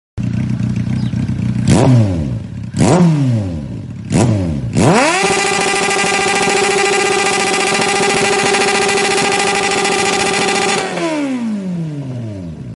patay lang motor HAHAHAH
Honda dio1